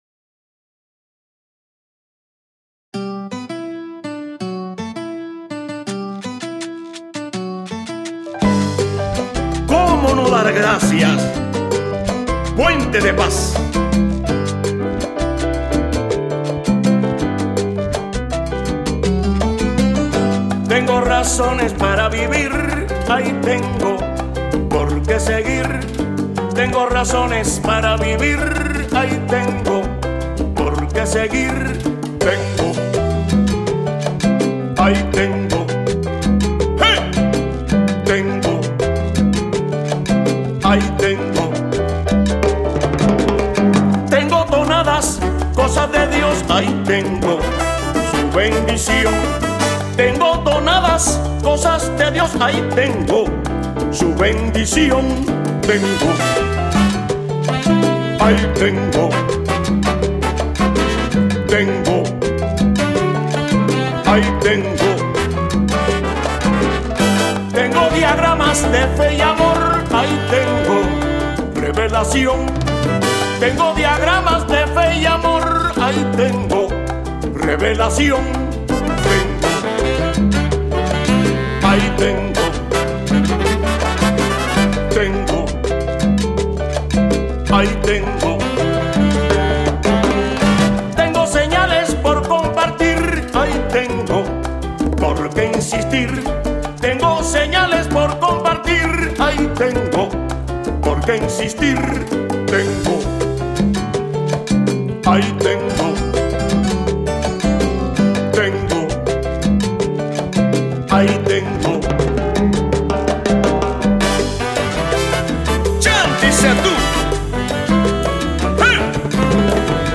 sonero